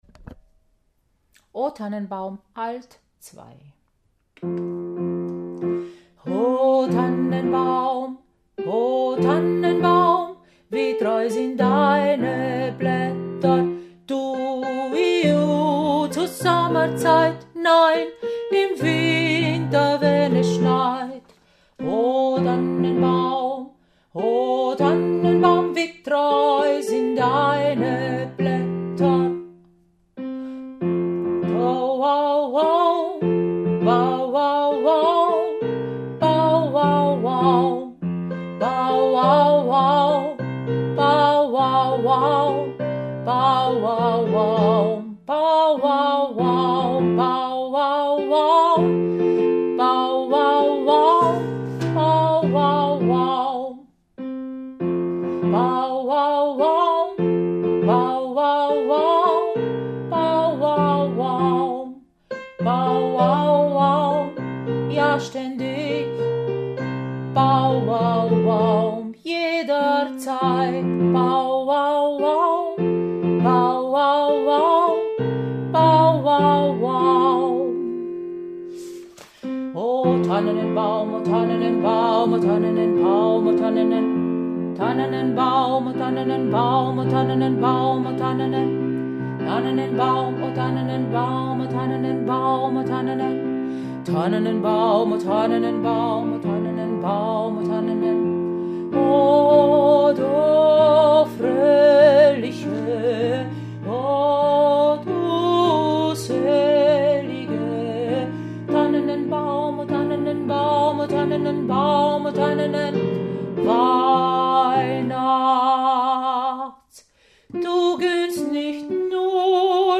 Oh Tannenbaum Alt 2
Oh-Tannenbaum-A2.mp3